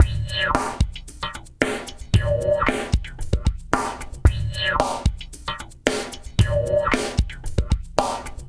LQT FUNK M-L.wav